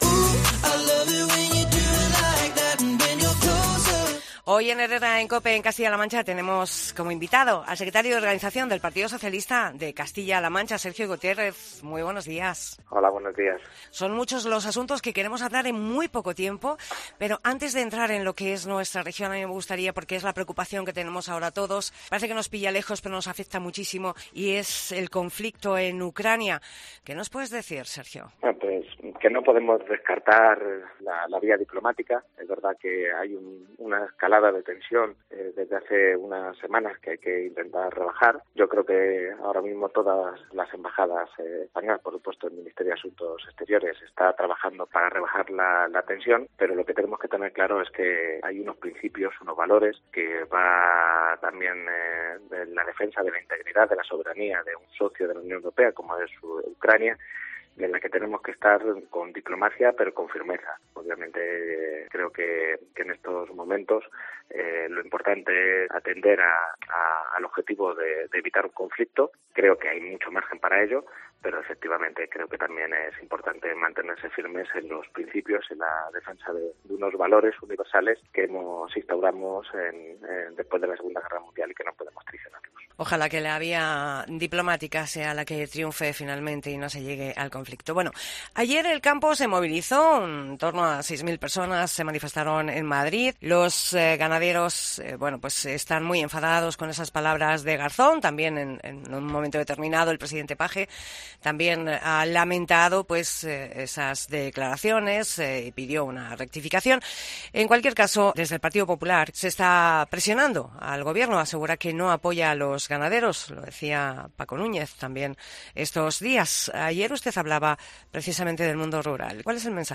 Entrevista a Sergio Gutiérrez Secretario de Organización PSOE CLM